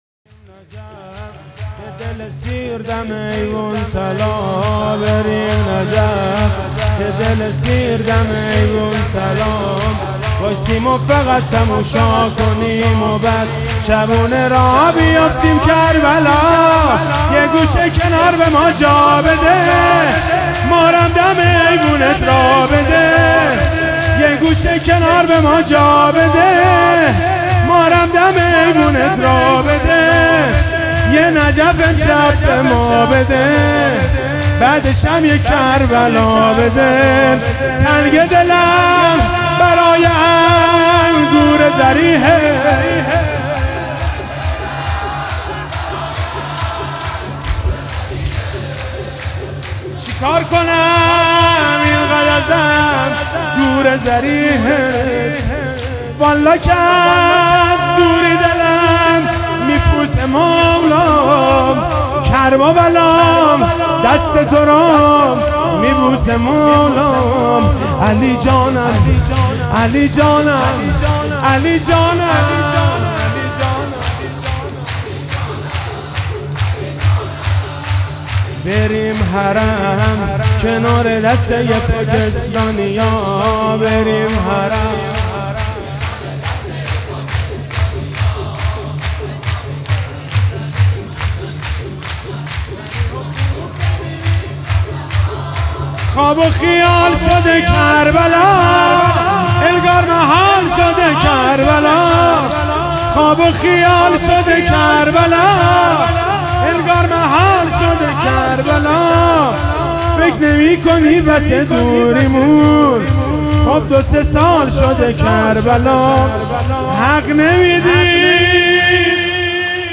ولادت حضرت امام علی(علیه السلام) 1400